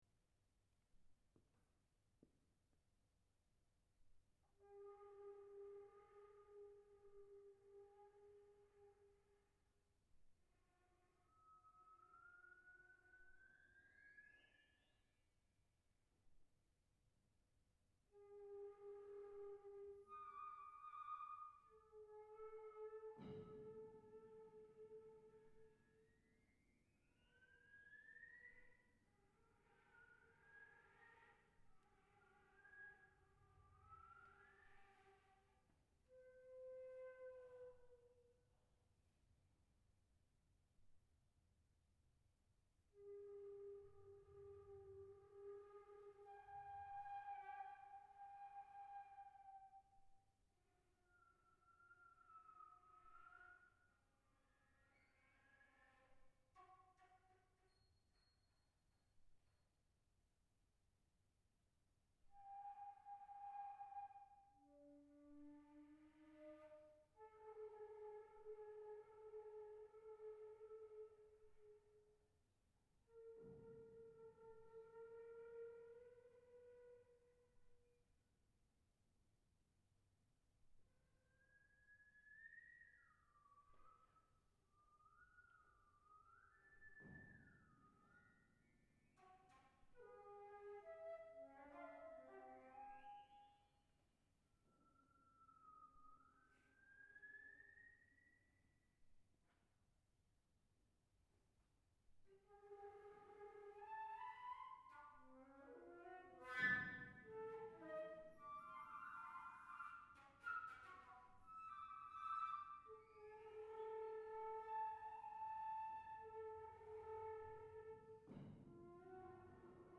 Instrumentation ： Flute and Piano
at Tokyo College of Music